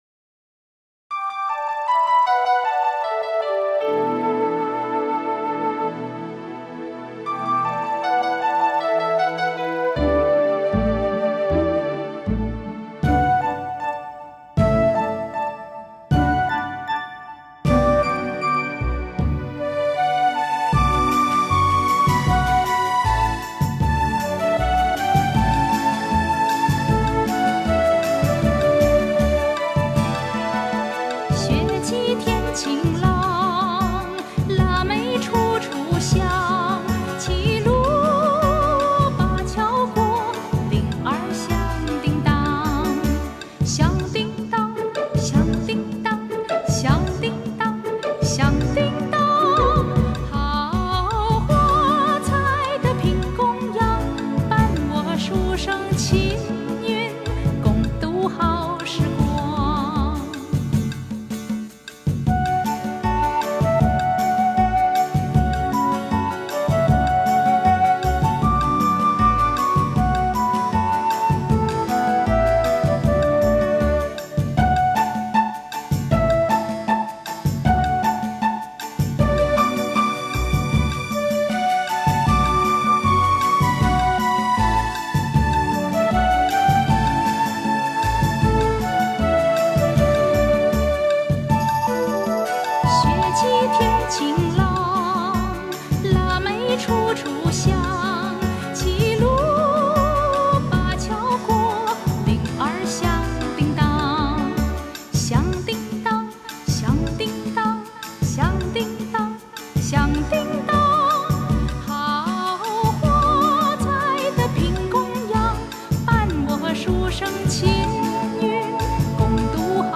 集合中西乐手
以现代电子合成乐融汇东方古老禅思哲意